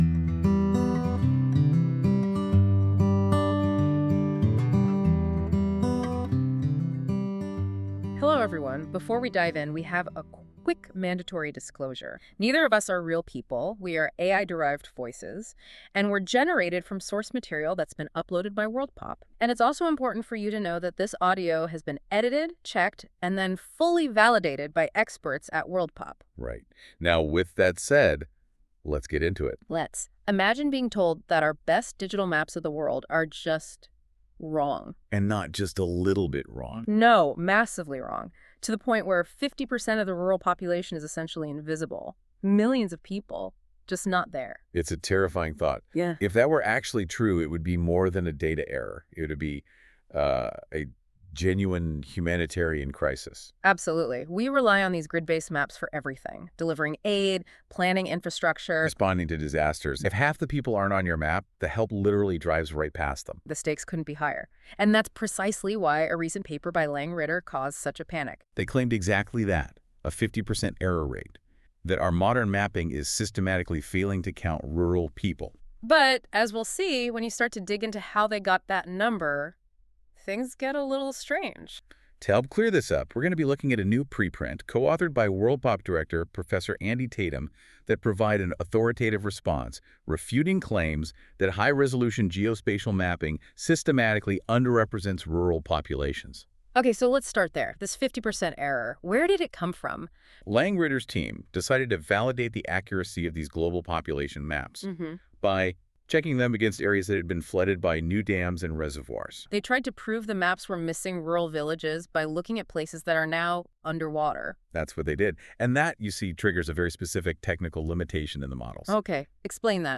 This feature uses AI to create a podcast-like audio conversation between two AI-derived hosts that summarise key points of documents - in this case the full preprint article linked below.
Music: My Guitar, Lowtone Music, Free Music Archive (CC BY-NC-ND)